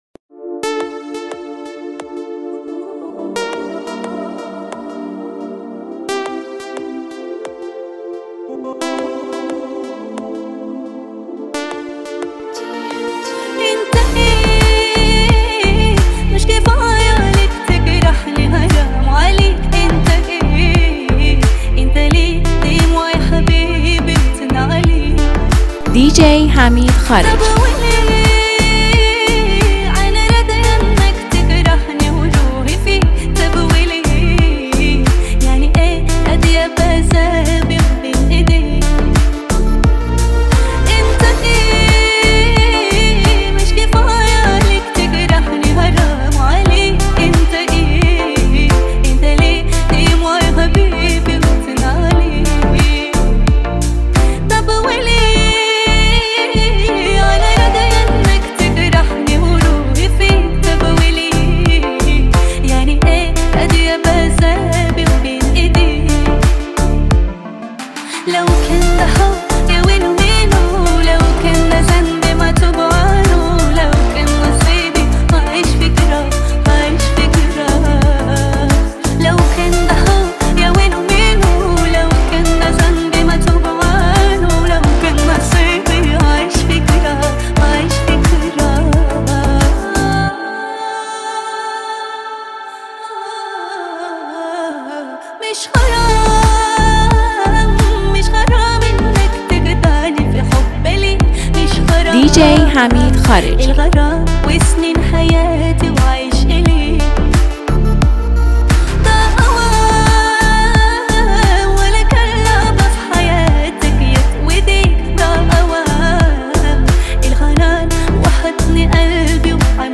بیس دار و مخصوص کلاب